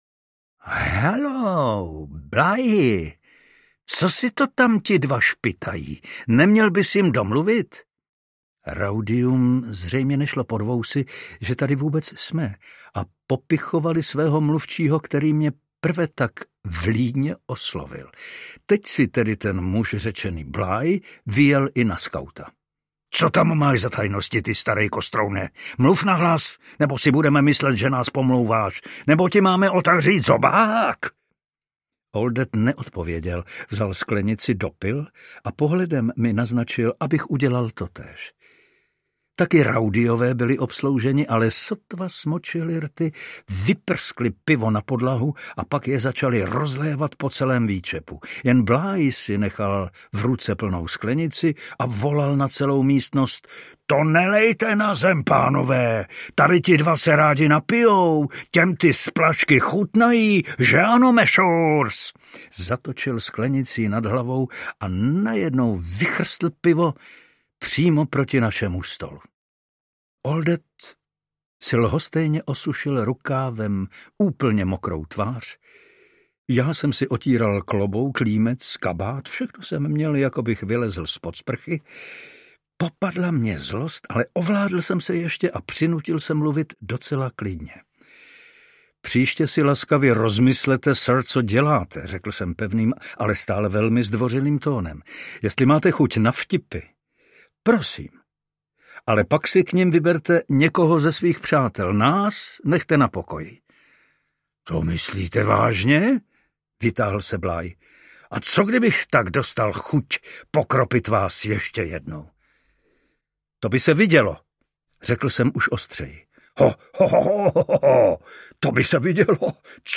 Vinnetou - Rudý gentleman audiokniha
Ukázka z knihy
Vyrobilo studio Soundguru.
vinnetou-rudy-gentleman-audiokniha